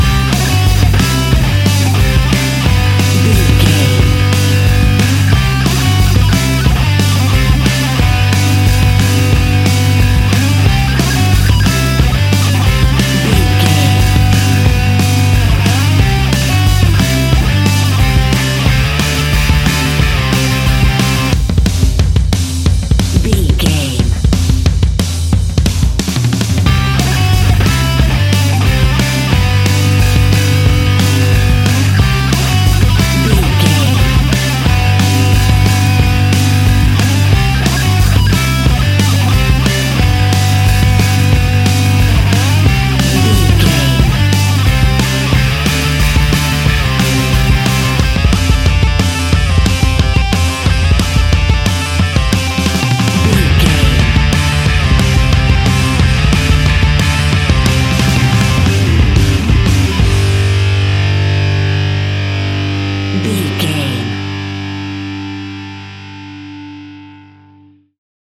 Fast paced
Ionian/Major
hard rock
distortion
punk metal
instrumentals
Rock Bass
Rock Drums
distorted guitars
hammond organ